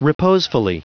Prononciation du mot reposefully en anglais (fichier audio)
Prononciation du mot : reposefully